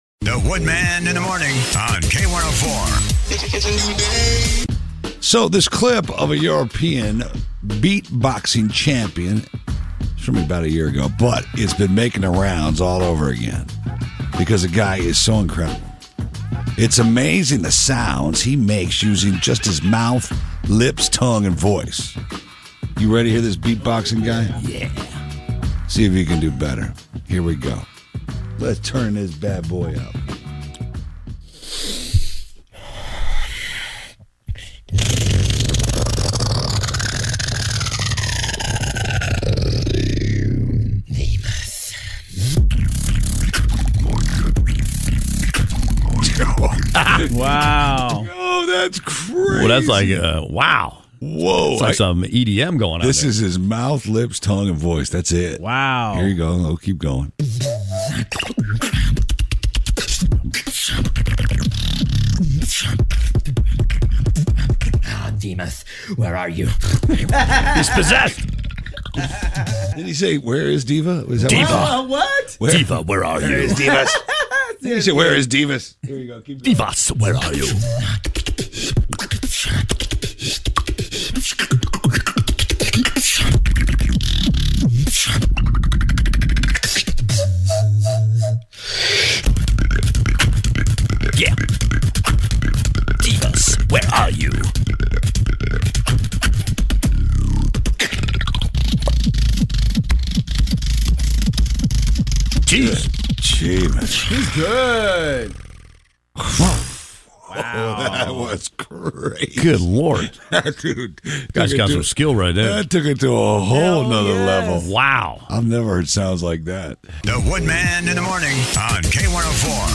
beatboxxxxxx